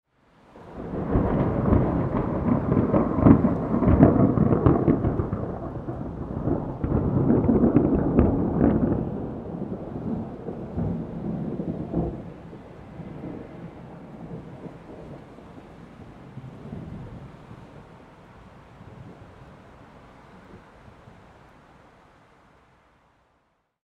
Ambient Thunder Sound Effect – Deep Rumbling Storm Atmosphere
Experience a realistic ambient thunder sound effect with deep rumbling and natural storm sounds.
Perfect for creating a dramatic, immersive atmosphere in any project.
Genres: Sound Effects
Ambient-thunder-sound-effect-deep-rumbling-storm-atmosphere.mp3